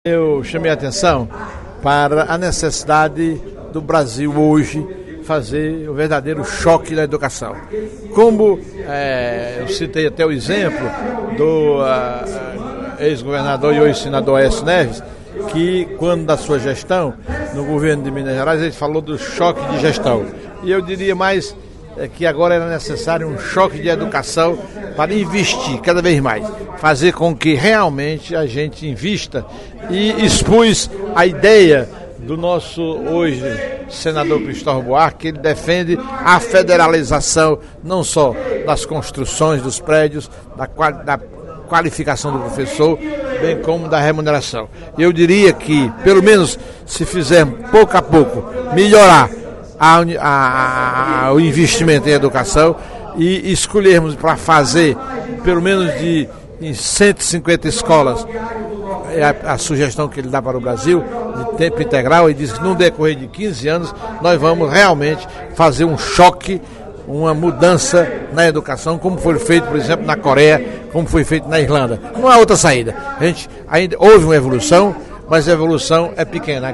O deputado Professor Teodoro (PSD) afirmou, na sessão plenária da Assembleia Legislativa nesta quarta-feira (08/02), que o Brasil precisa de um choque de educação, para que o país alcance o grau dos países desenvolvidos.